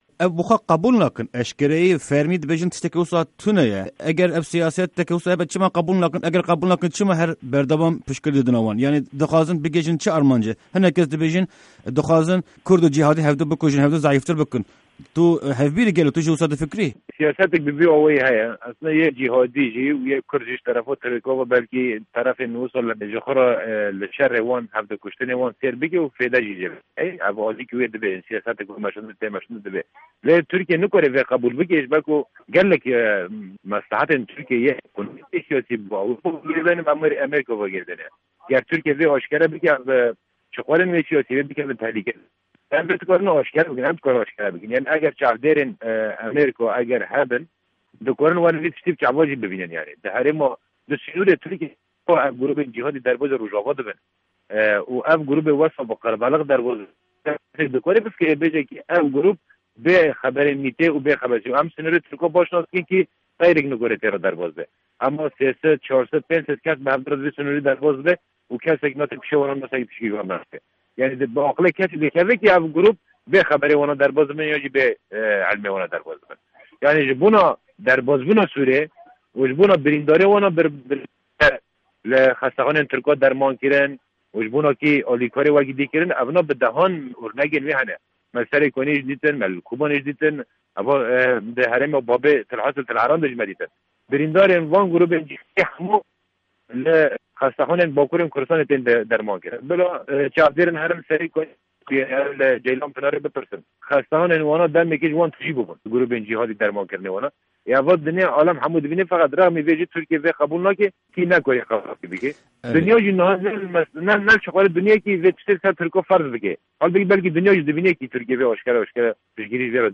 Di hevpeyvîna taybet ya Dengê Amerîka de Sîpan Hemo, Fermandarê Giştî yê Yekîneyên Parastine Gel (YPG) agahîyên girîng li ser şerên berdewam dide û pêşketinên dawî dinirxîne.